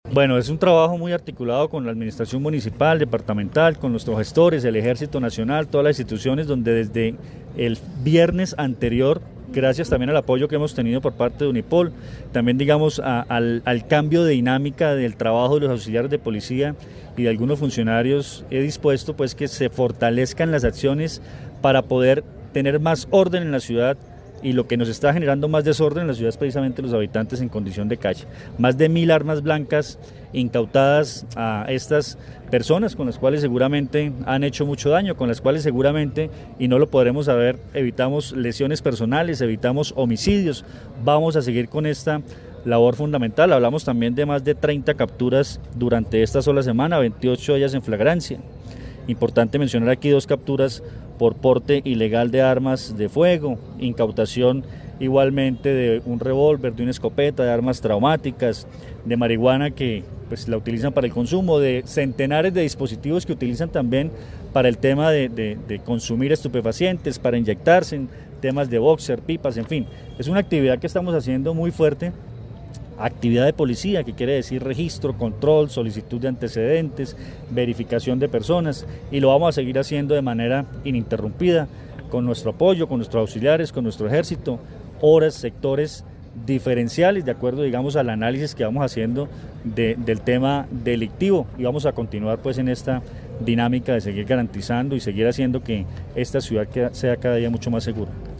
Coronel Luis Fernando Atuesta sobre operativos contra habitantes de calle
El coronel Luis Fernando Atuesta, comandante de la Policía del departamento dijo que realizan un trabajo articulado con el Ejército y la alcaldía de Armenia para generar el orden porque la mayor alteración de orden público tiene que ver con los habitantes de calle.